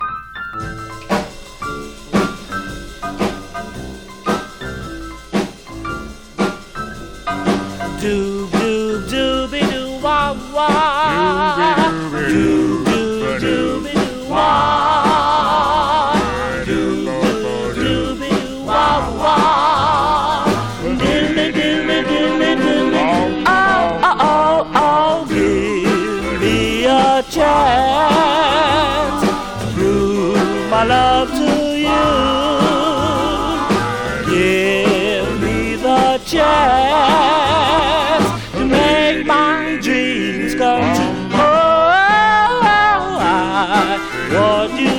アップテンポもバラードもお手のモノ。若さと勢いを感じる録音の数々。曲によりちょっと不安定なボーカルも最高。
Rock’N’Roll, Doo Wop　USA　12inchレコード　33rpm　Mono